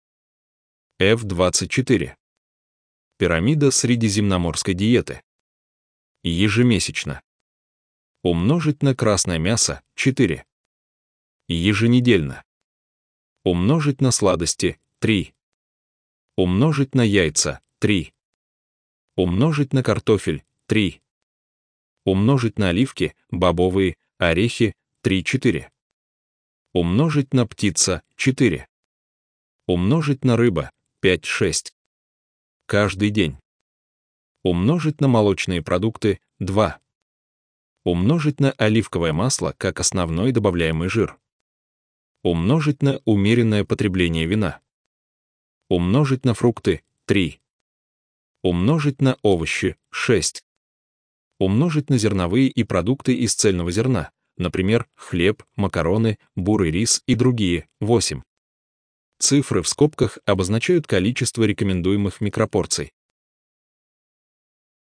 Аудиогид